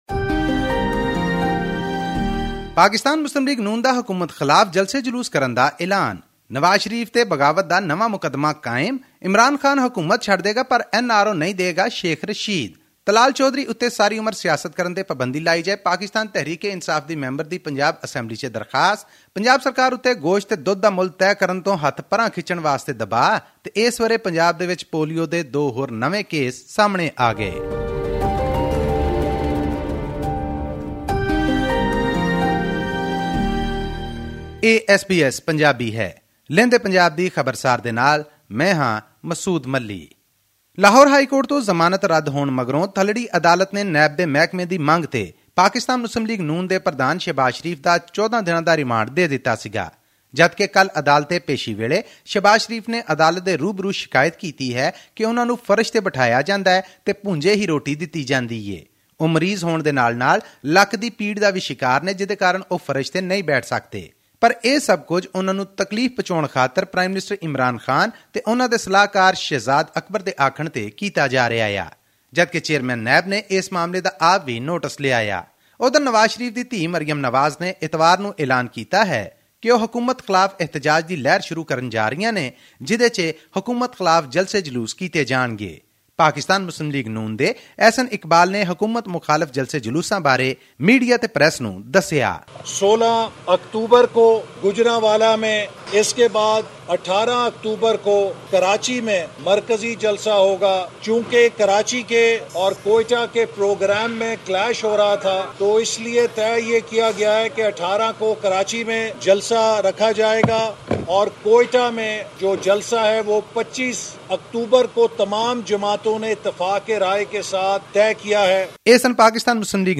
To hear the full bulletin, please click on the audio player above.